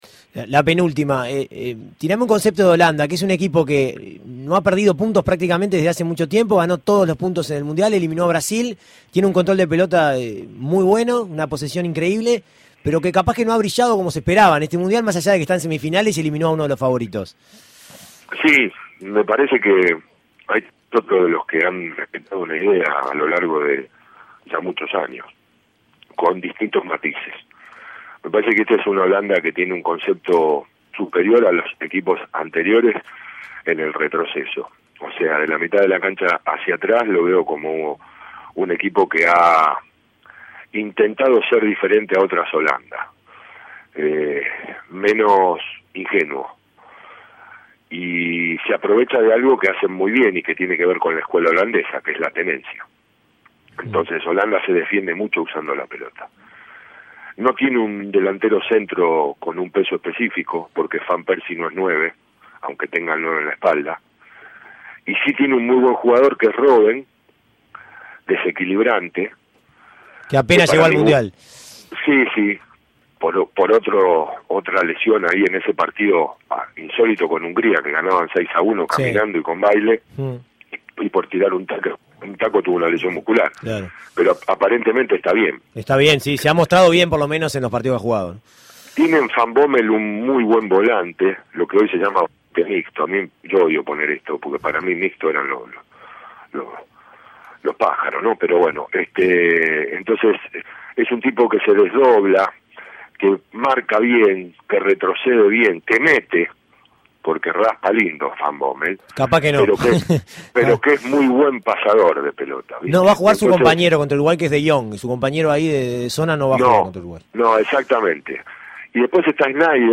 Goles y comentarios Tercera parte de la entrevista